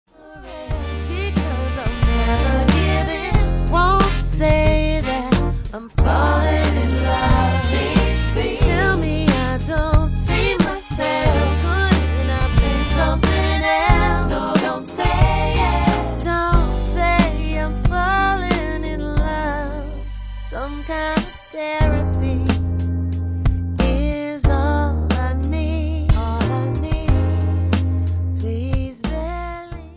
NOTE: Vocal Tracks 1 Thru 9